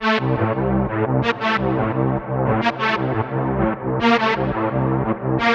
Convoy Strings 03.wav